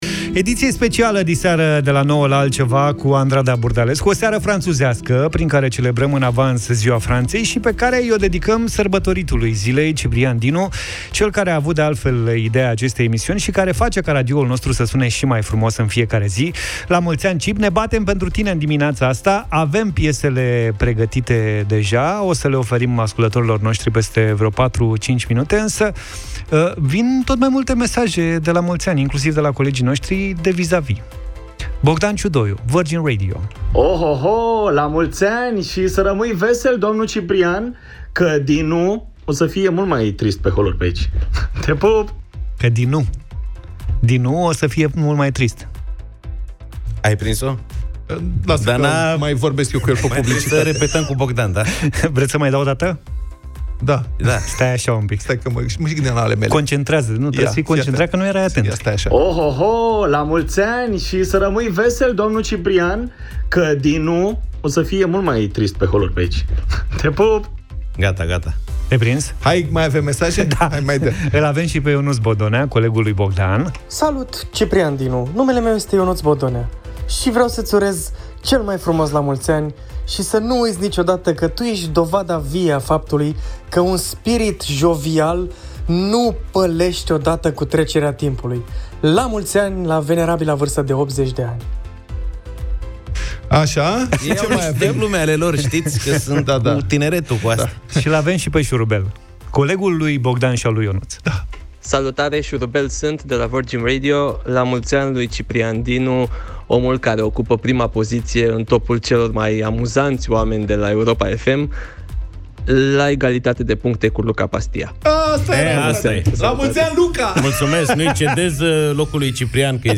i-au urat și ei, în direct, “La mulți ani”.